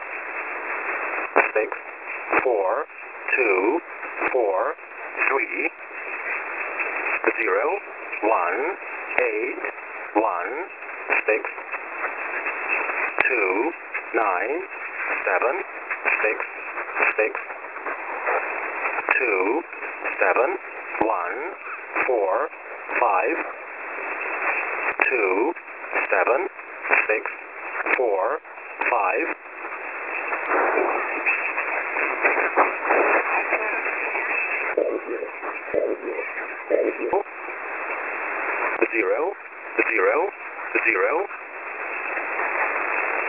Mode: USB
Comments: recording started late